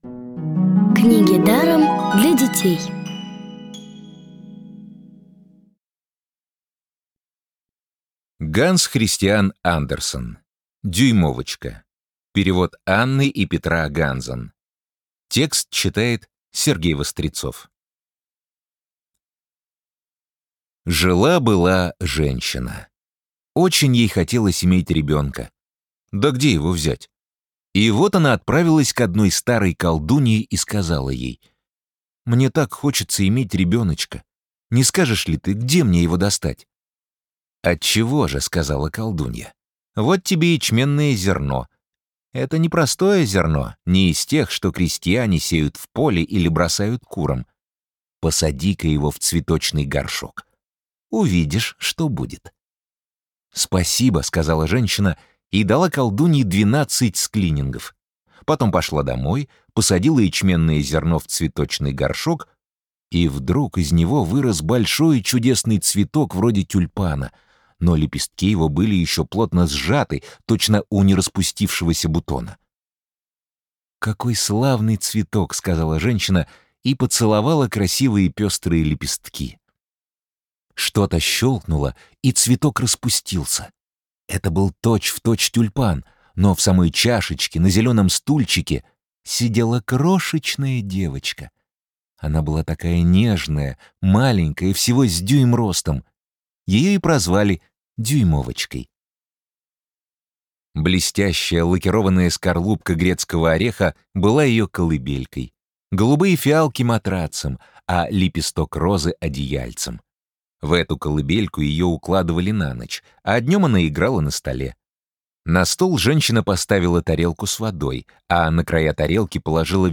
Аудиокнига бесплатно «Дюймовочка» от Рексквер.
Аудиокниги онлайн – слушайте «Дюймовочку» в профессиональной озвучке и с качественным звуком. Ханс Кристиан Андерсен - Дюймовочка.